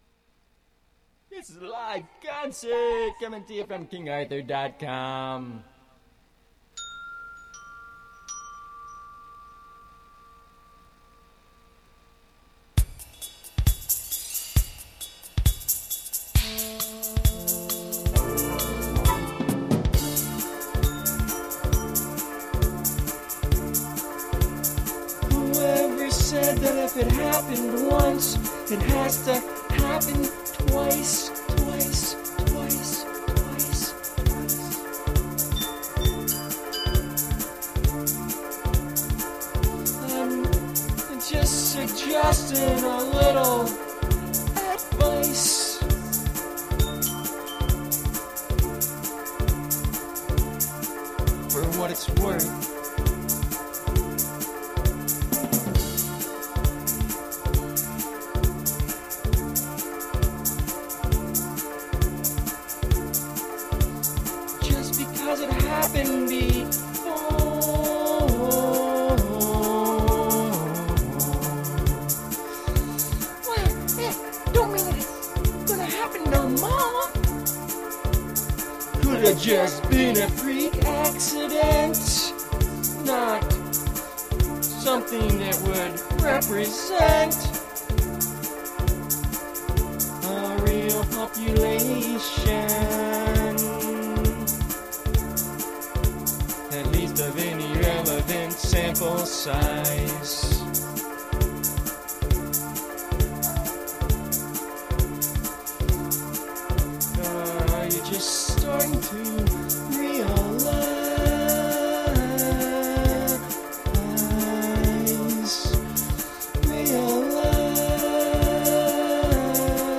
Ogg Vorbis live modern music concert, show, and performance.